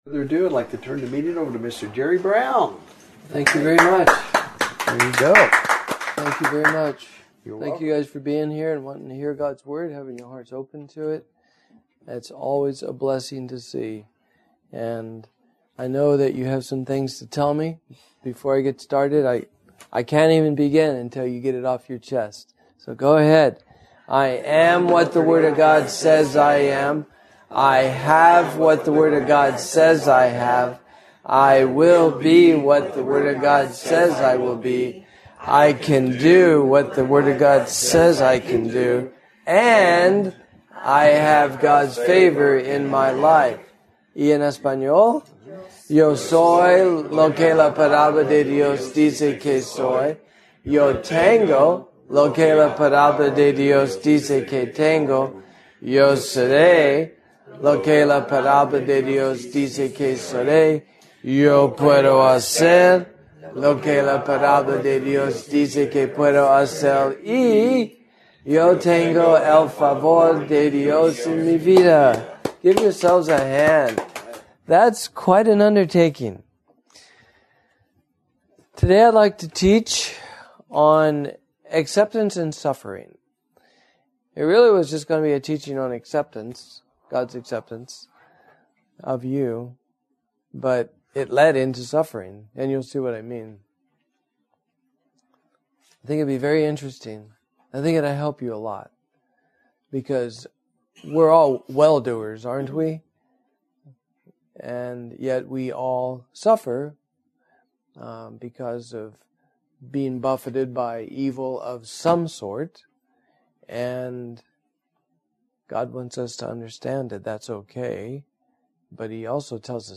God has accepted us as His own! This teaching begins to focus on how important knowing this truth truly is, especially when any suffering is happening in life!